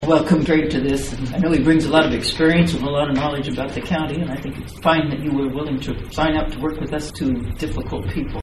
During the meeting, Commissioner Dee McKee thanked Riat for his decision to join the commission alongside her and Commissioner Pat Weixelman.